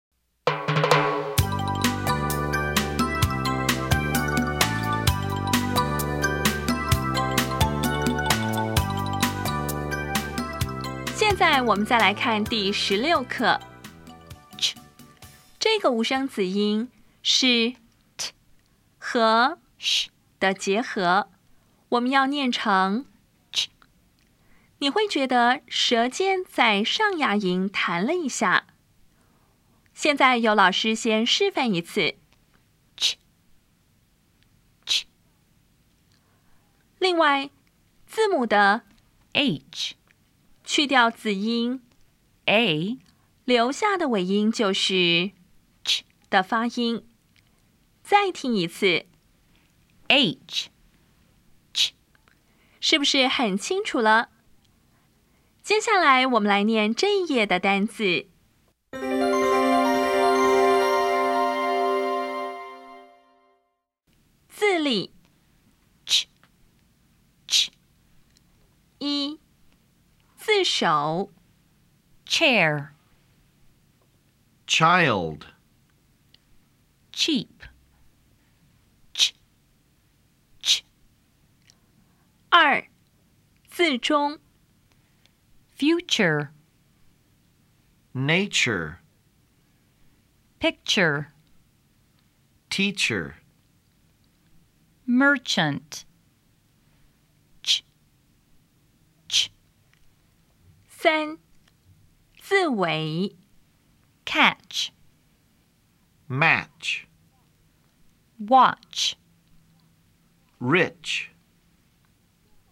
[tʃ]
音标讲解第十六课
[tʃɛr]
[tʃaɪld]